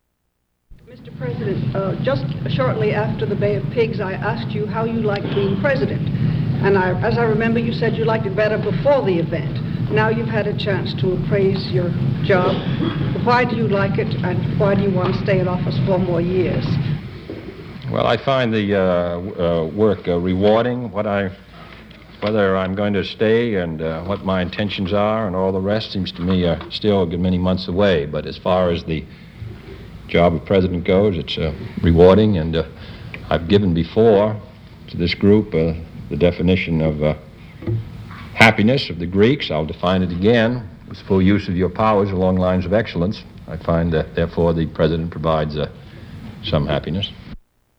Excerpt of U.S. President John F. Kennedy speaking at a press conference